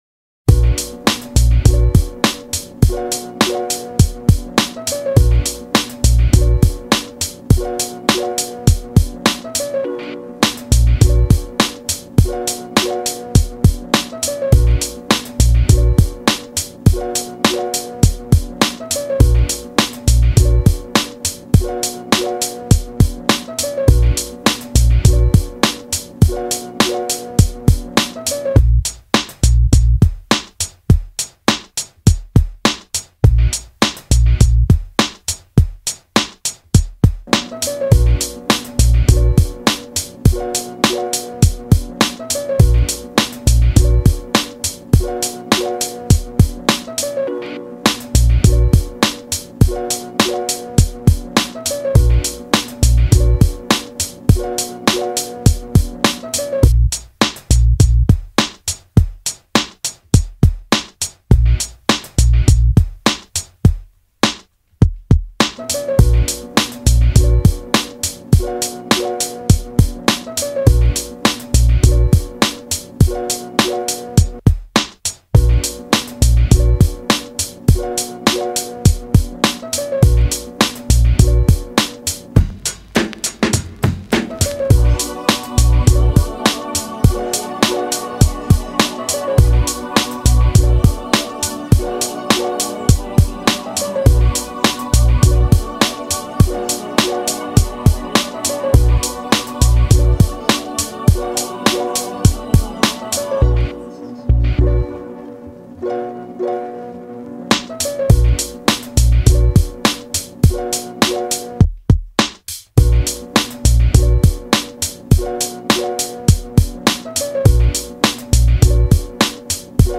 Rock Instrumental